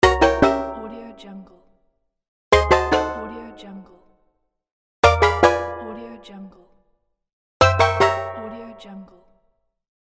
دانلود افکت صدای شکست کوچک
Sample rate 16-Bit Stereo, 44.1 kHz
Looped No